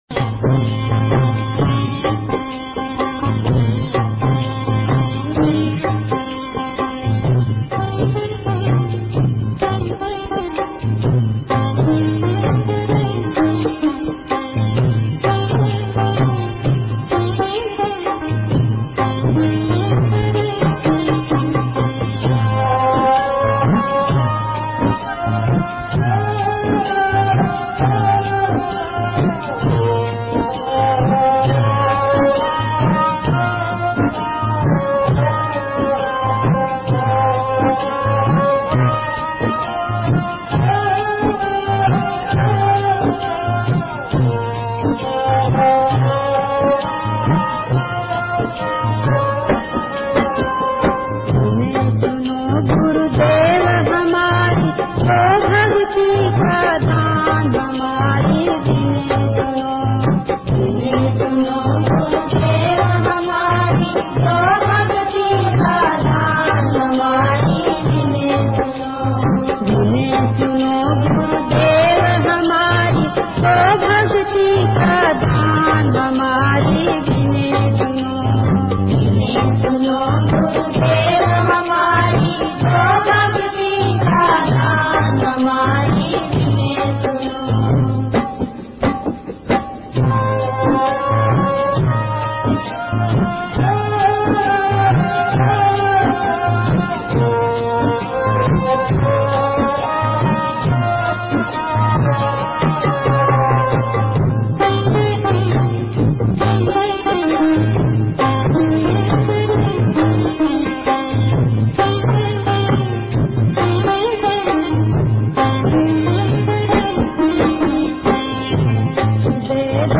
Bhajan 10- Vinti Suno Guru Dev